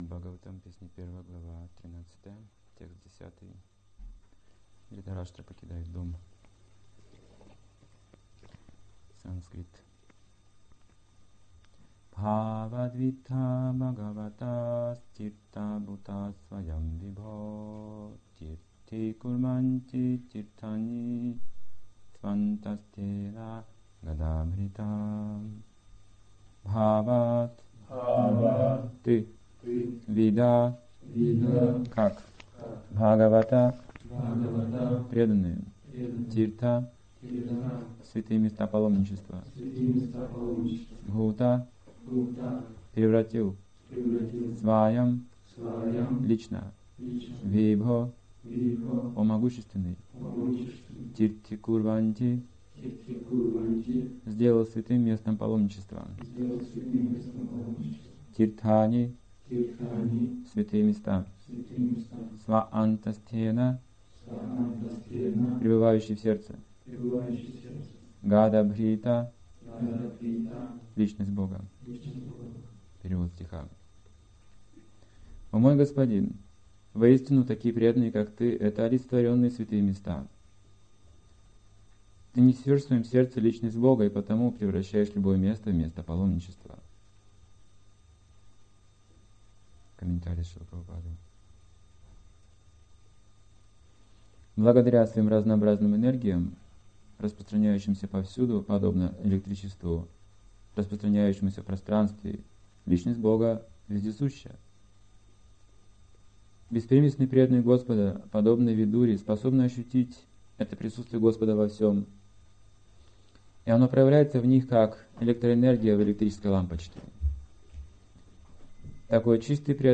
Темы, затронутые в лекции: Святые личности неотличны от мест паломничества Дхама - это место явления Самого Господа и оно неотлично от Него, это духовный мир Материальная энергия - это инструмент для развития привязанности к духовному Смысл жизни - достичь духовного совершенства Цель паломничества - общение со святыми Духовный источник проникает даже сквозь ложное эго Искусство проповеди Тело - это тип